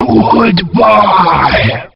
Line of Bubbler in Diddy Kong Racing.
Blubber_(goodbye).oga.mp3